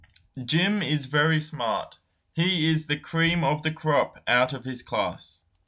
英語ネイティブによる発音はこちらです。